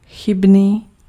Ääntäminen
IPA: [mo.vɛ]